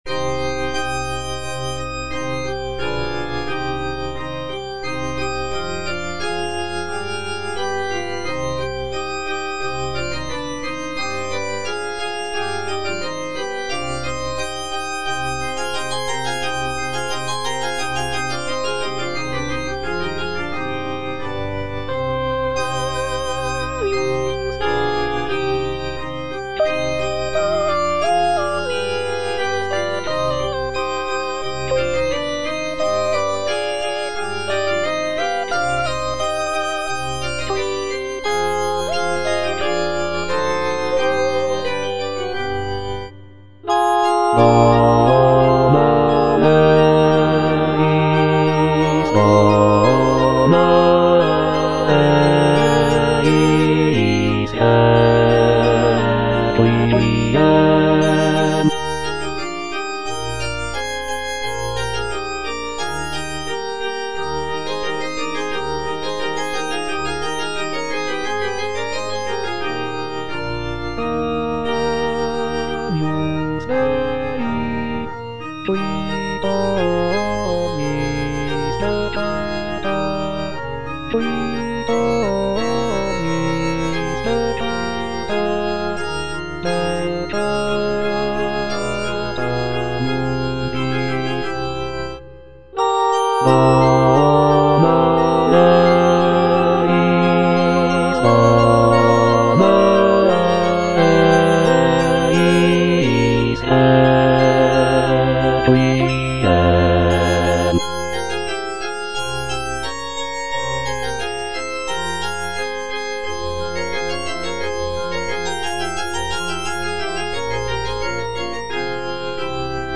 M. HAYDN - REQUIEM IN C (MISSA PRO DEFUNCTO ARCHIEPISCOPO SIGISMUNDO) MH155 Agnus Dei - Bass (Emphasised voice and other voices) Ads stop: auto-stop
It was written as a requiem mass in memory of Archbishop Sigismund von Schrattenbach. The work is characterized by its somber and mournful tone, reflecting the solemnity of a funeral mass.